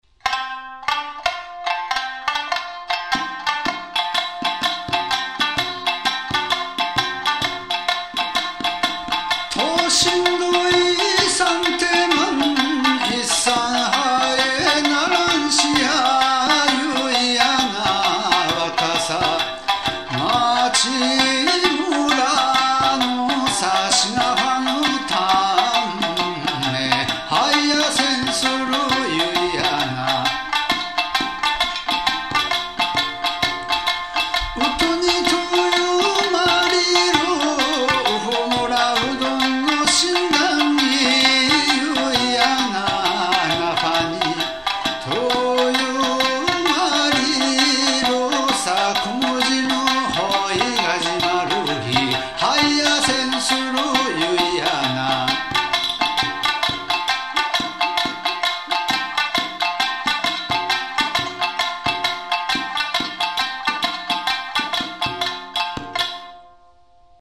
歌三線
三線　笛　太鼓　三板